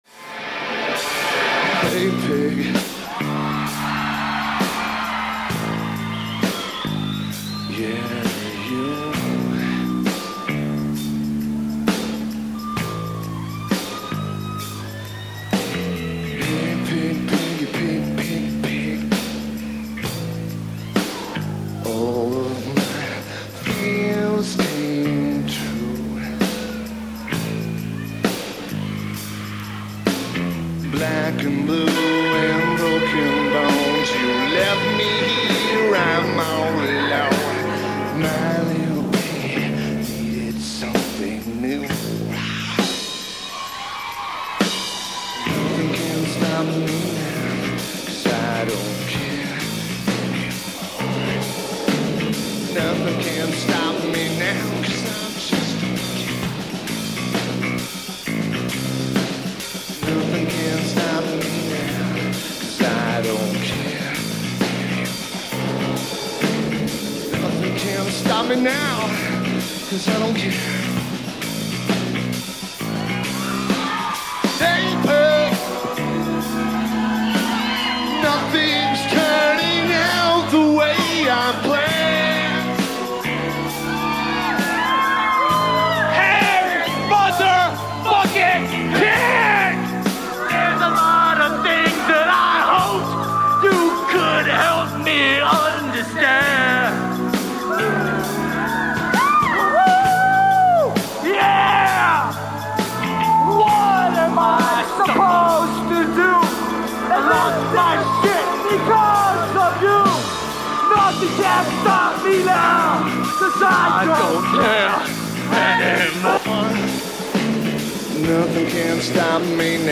Key Club at Morongo Casino (Spiral Only Show)
Lineage: Audio - IEM (ICOM IC-R5 + Sony MZ-N707) - Show
Probably one of the better IEM recordings I have heard.